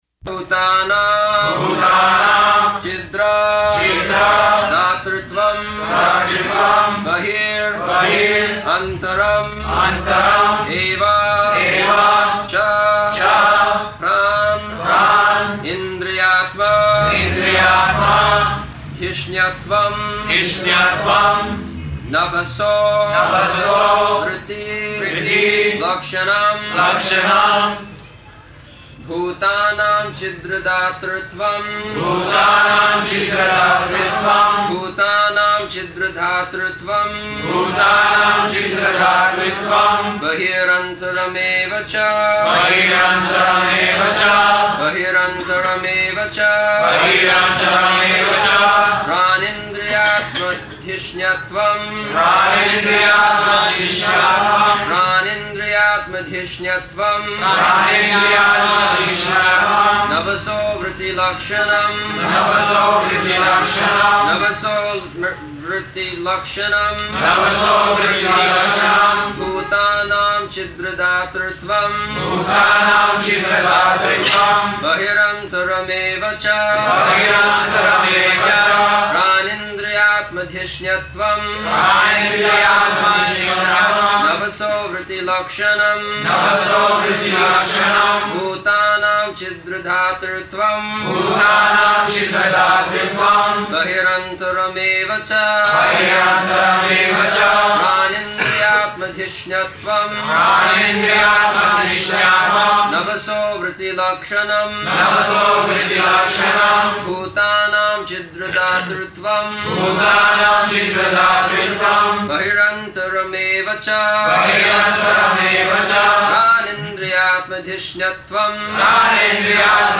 -- Type: Srimad-Bhagavatam Dated: January 11th 1975 Location: Bombay Audio file